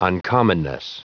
Prononciation du mot uncommonness en anglais (fichier audio)
Prononciation du mot : uncommonness